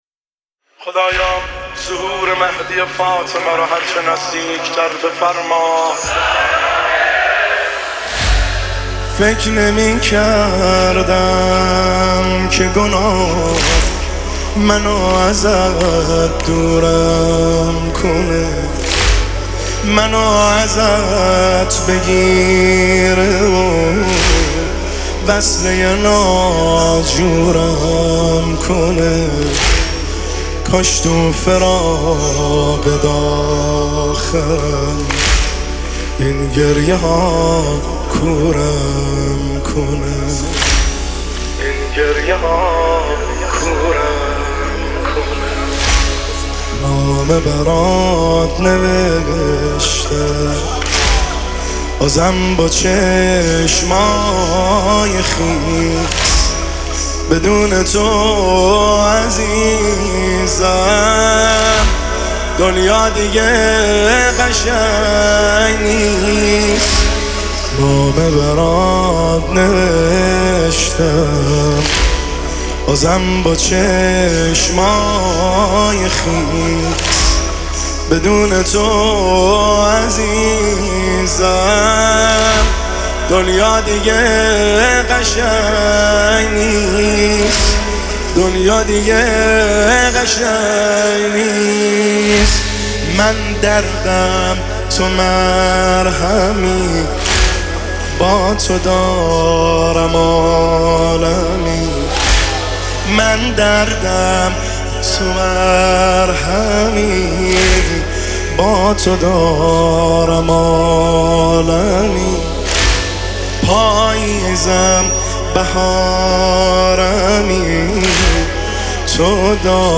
نماهنگ و مناجات مهدوی